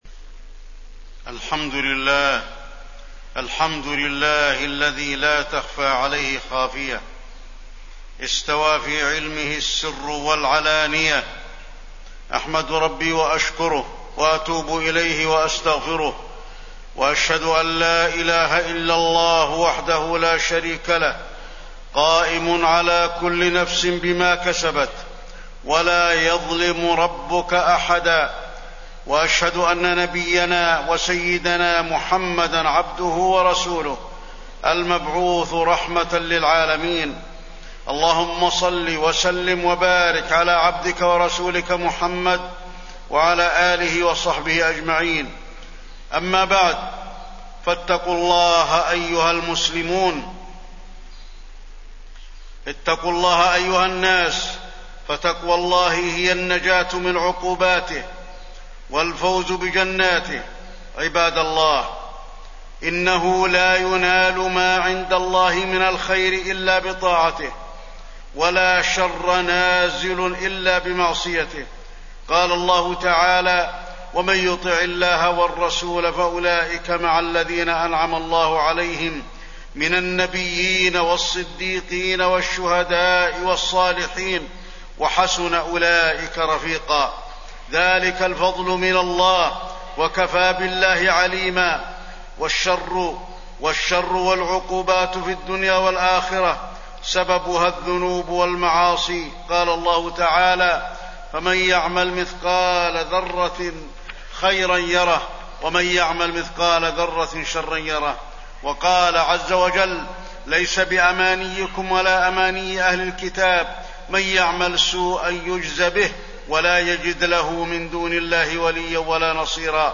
تاريخ النشر ٢١ جمادى الآخرة ١٤٣١ هـ المكان: المسجد النبوي الشيخ: فضيلة الشيخ د. علي بن عبدالرحمن الحذيفي فضيلة الشيخ د. علي بن عبدالرحمن الحذيفي غزة الجريحة